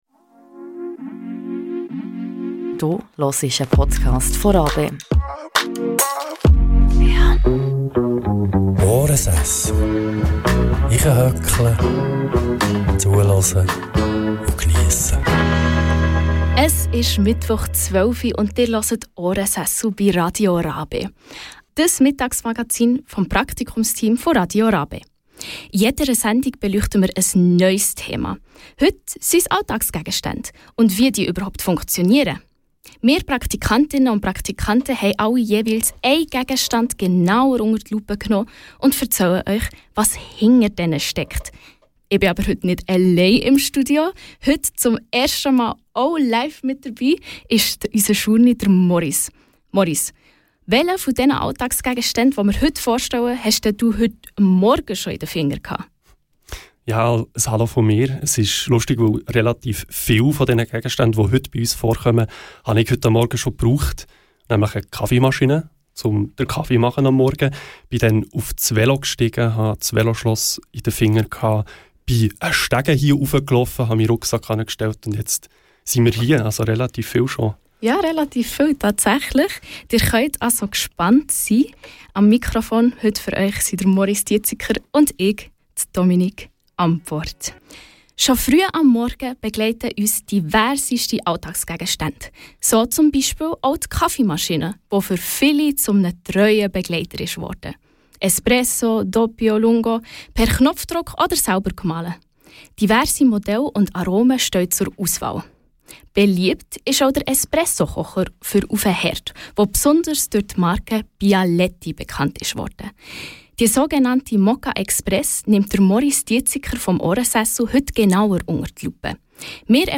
Dies ist ein Zusammenschnitt der RaBe-Sendung Ohrensessel.